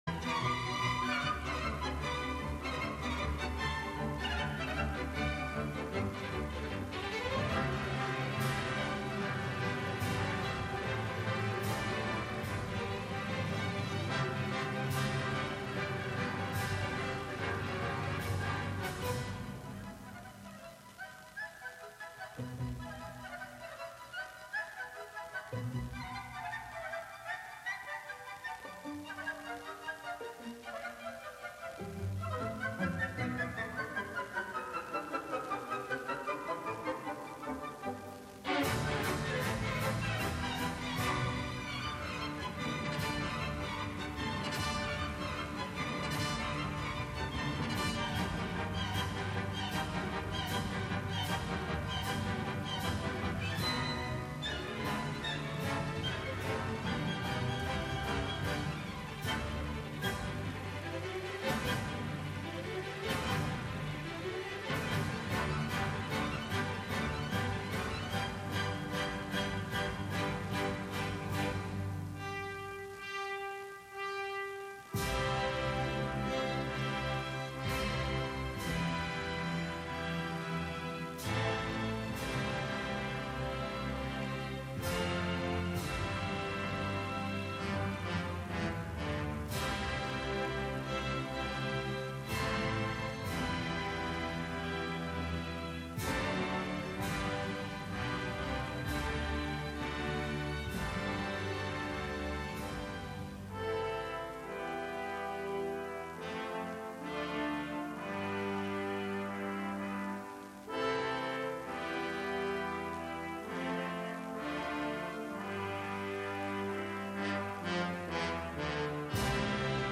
Live from Brooklyn, New York,